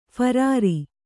♪ pharāri